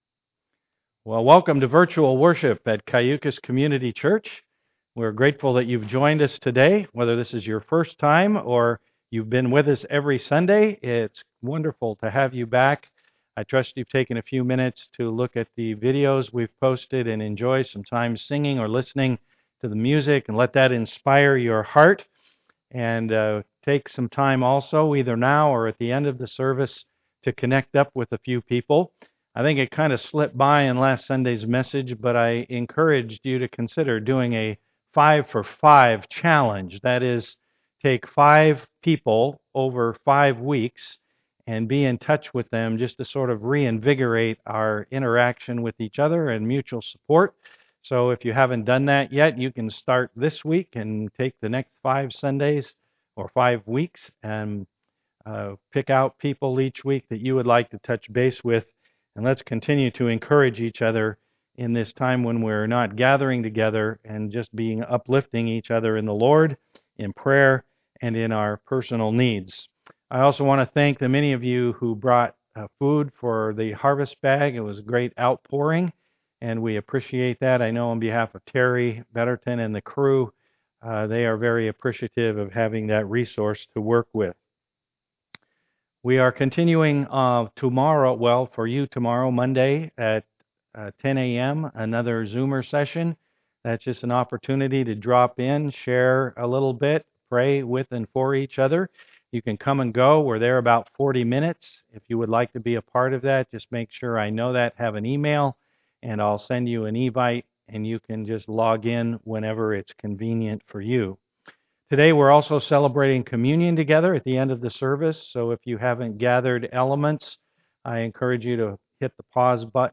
Passage: Exodus 3 Service Type: am worship